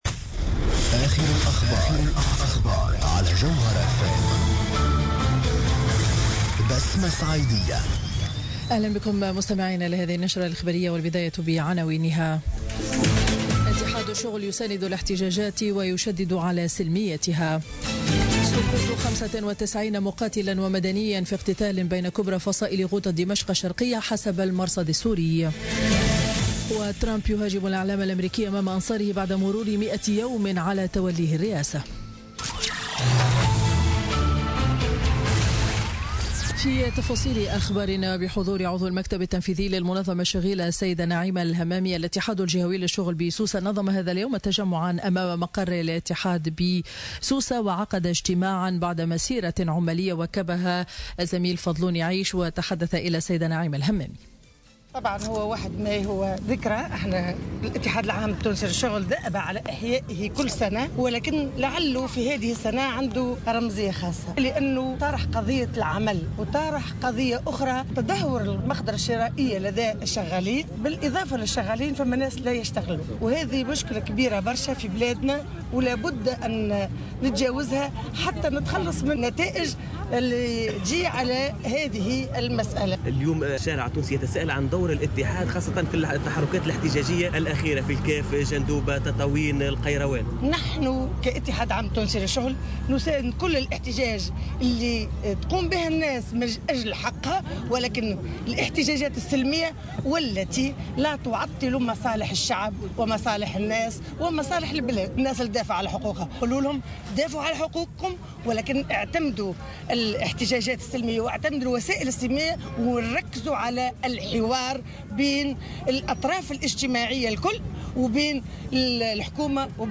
نشرة أخبار منتصف النهار ليوم الأحد 30 أفريل 2017